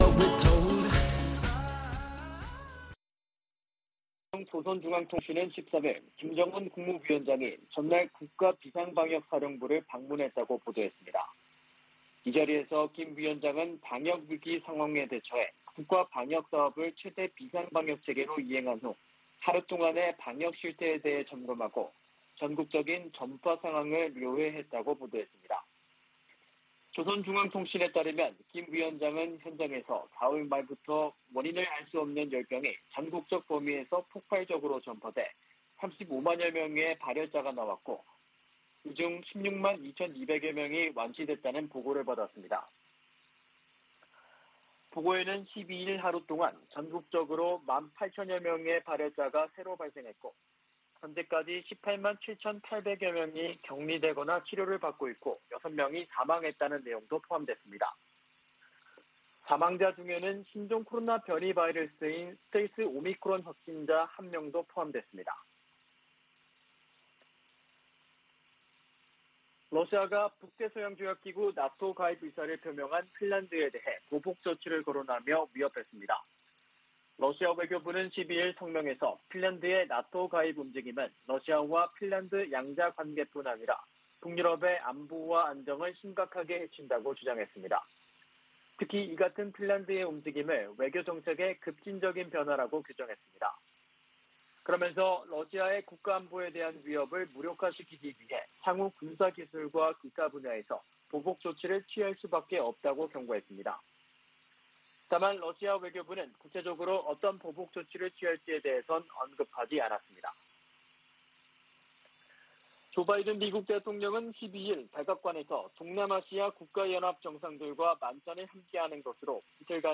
VOA 한국어 아침 뉴스 프로그램 '워싱턴 뉴스 광장' 2022년 5월 14일 방송입니다. 백악관은 북한이 이달 중 핵실험 준비를 끝낼 것으로 분석했습니다.